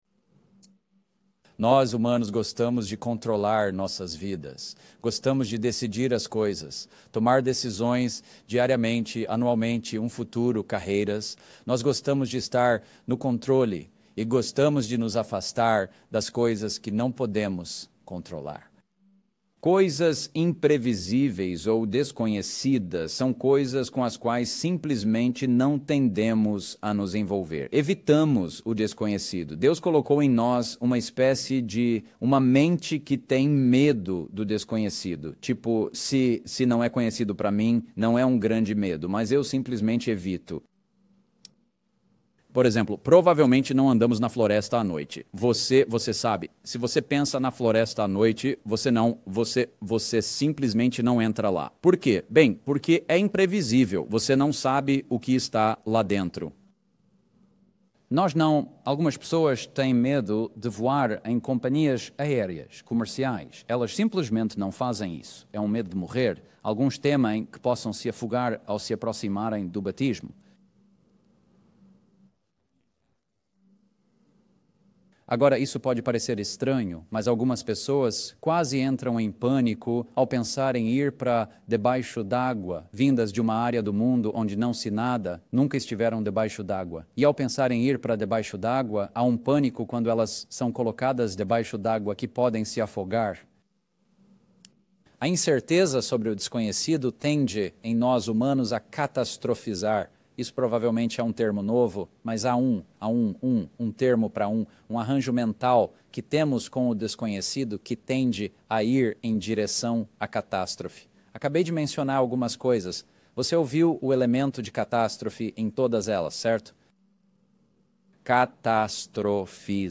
Given in Patos de Minas, MG